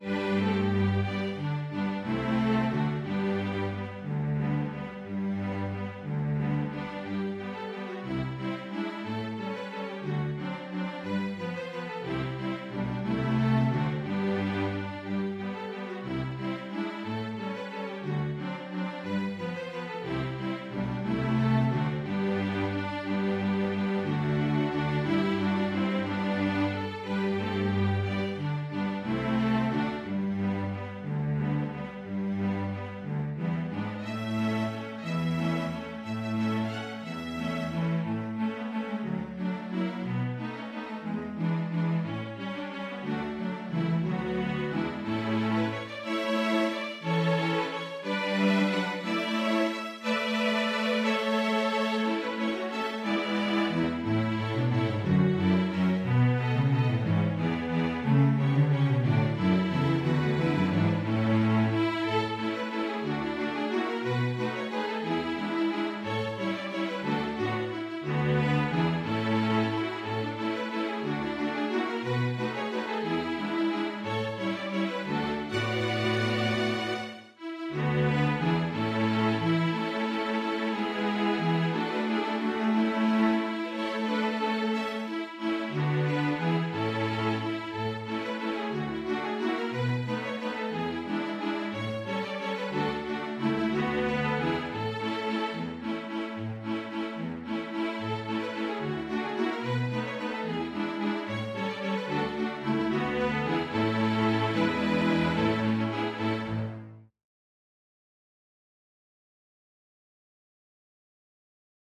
Composer: English Carol
Voicing: String Orchestra